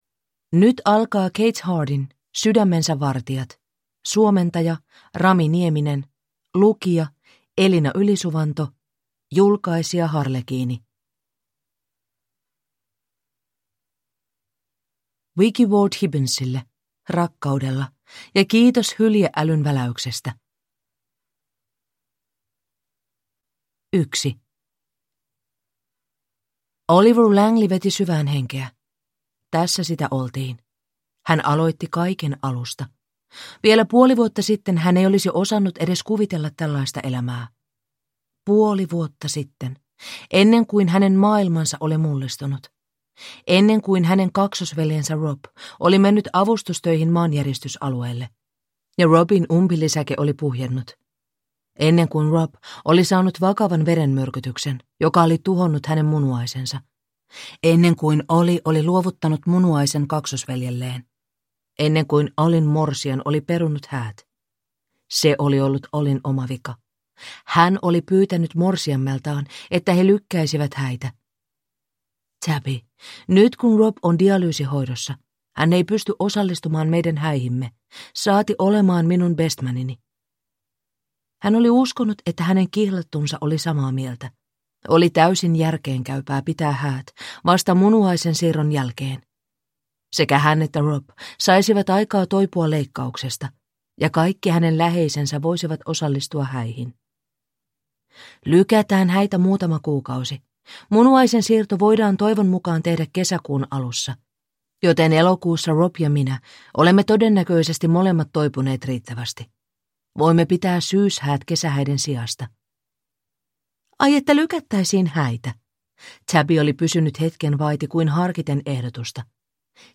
Sydämensä vartijat (ljudbok) av Kate Hardy